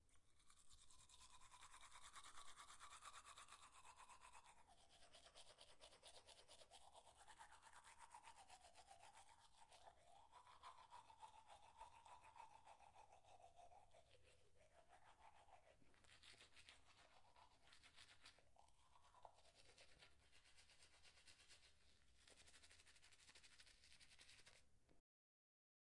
刷牙的浴室 Fx
由一个体面能量的年轻人刷过。